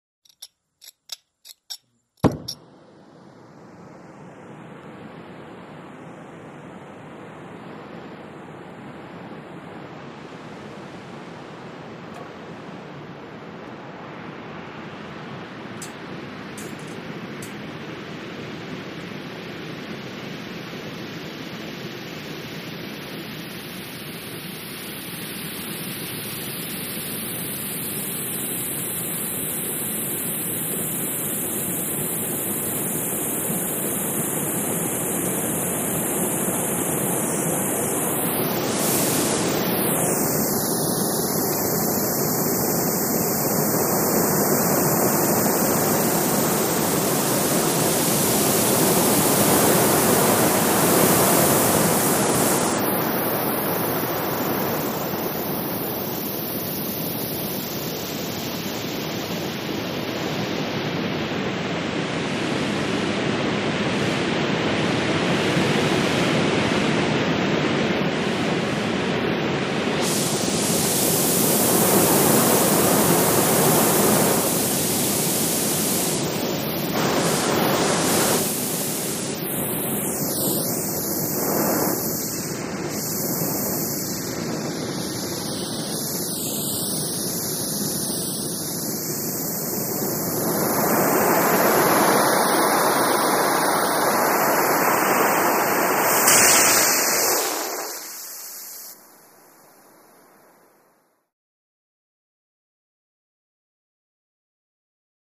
Blow Torch | Sneak On The Lot
Acetylene Blow Torch; Light, Then Strong Steady Run With ( Oxygen ) Hiss.